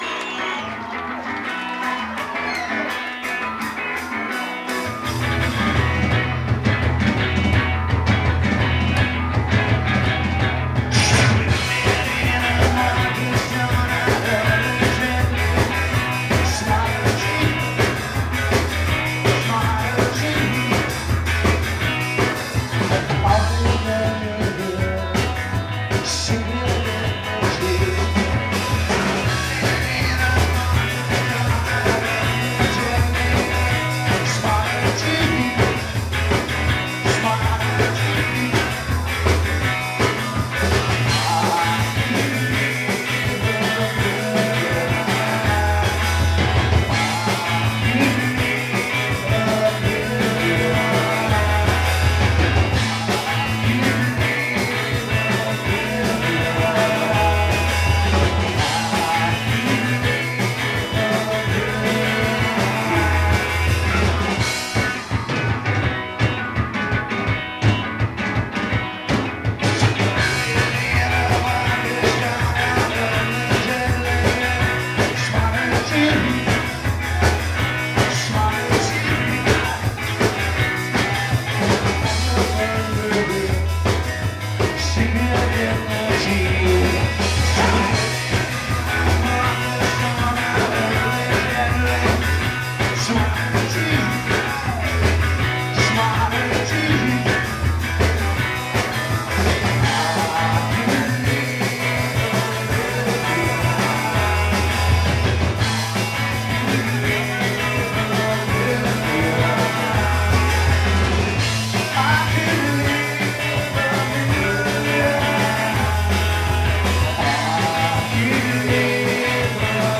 The Strand Cabaret, Marietta, GA